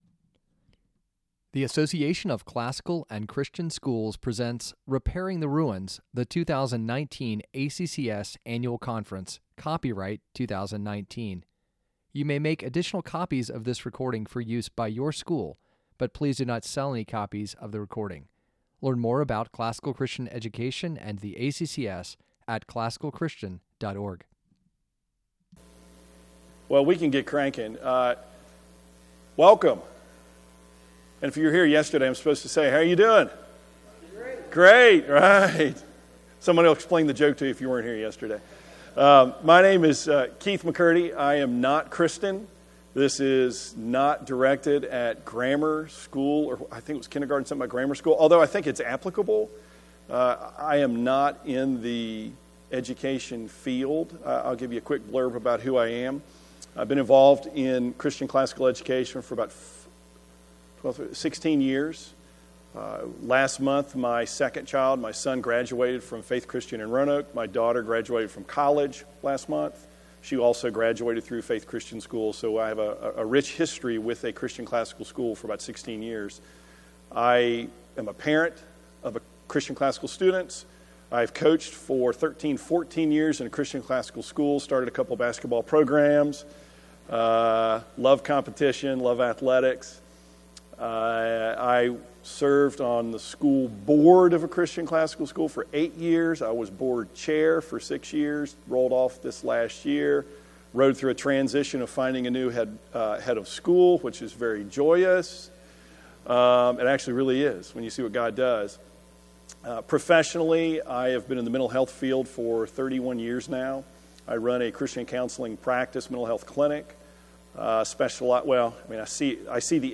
2019 Workshop Talk | 2019 | All Grade Levels